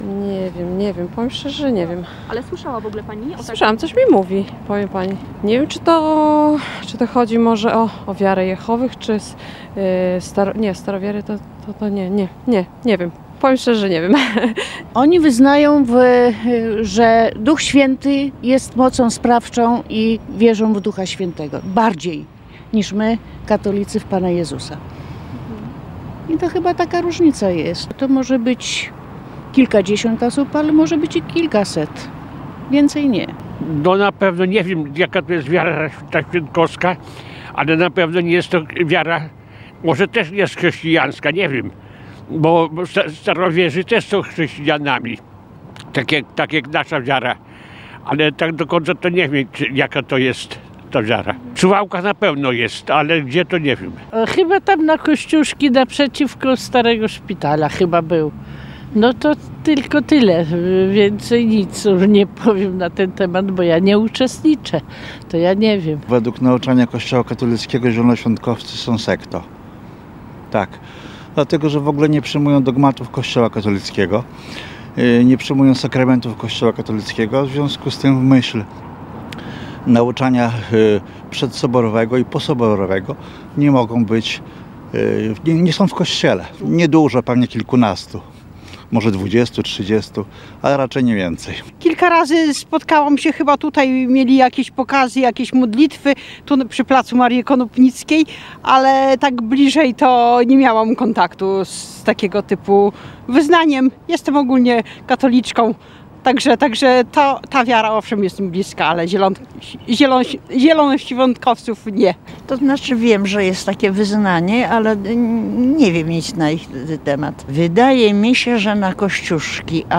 Z okazji jubileuszu zapytaliśmy suwalczan, co wiedzą na temat tego kościoła.
sonda.mp3